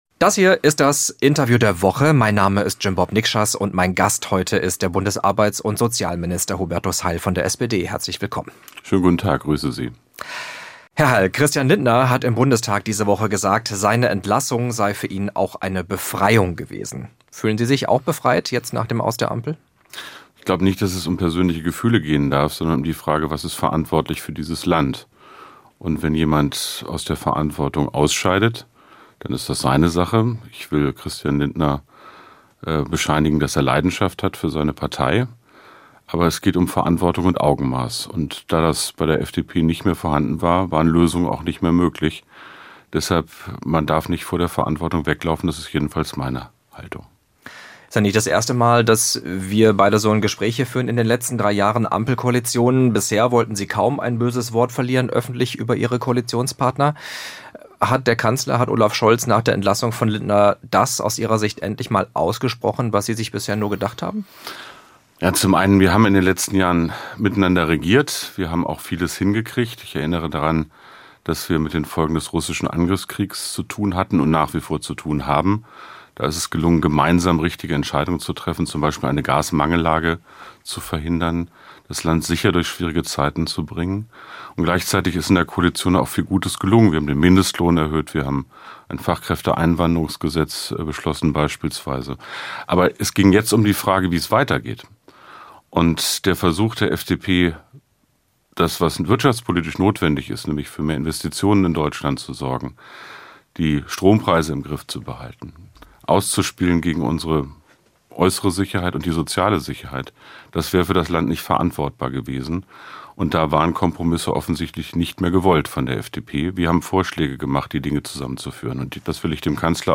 Politisches Interview